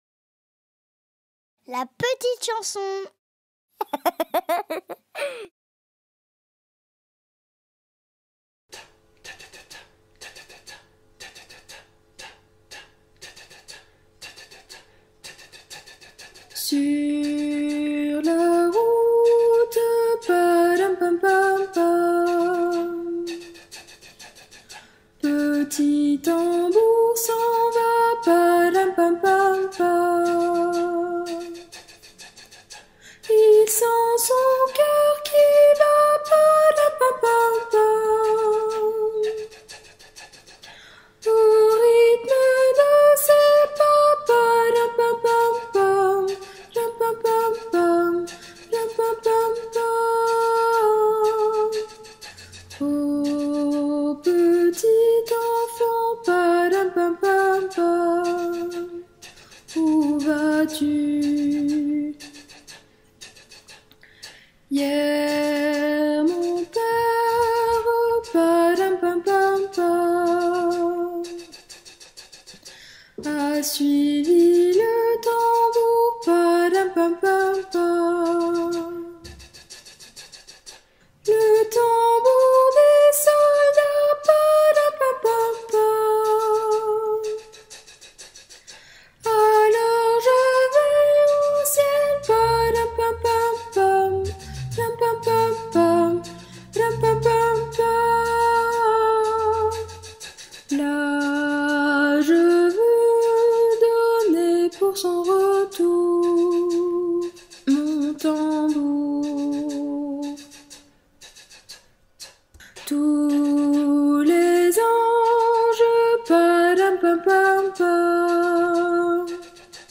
MP3 versions chantées
A 3 Voix Mixtes Voix 1 Soprano